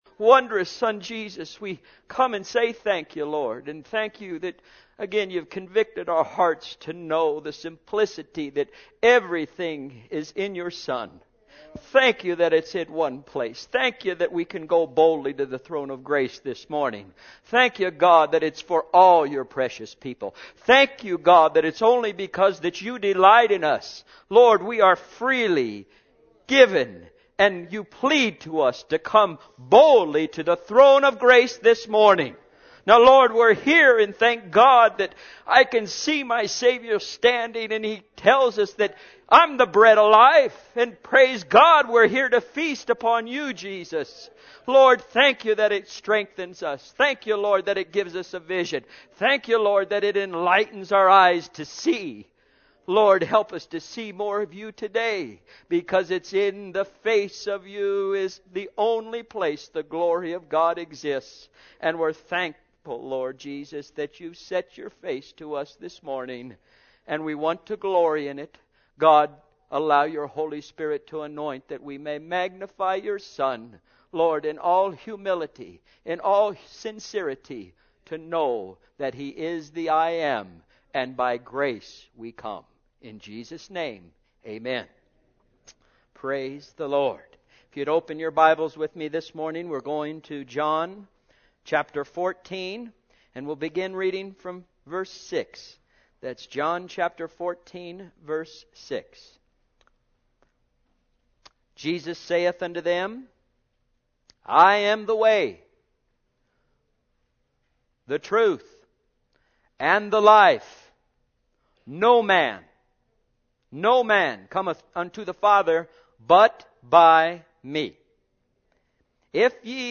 Sunday's Sermons for 2009